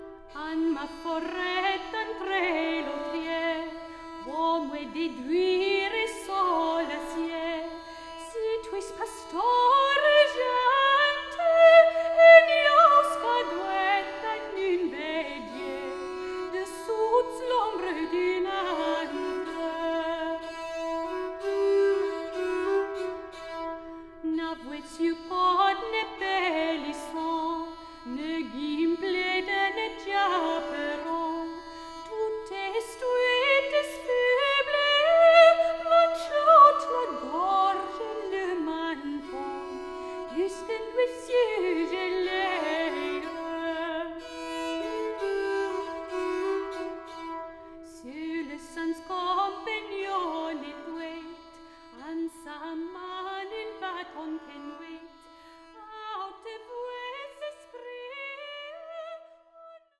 12th- and 13th-century troubadours and trouvères
soprano
vielle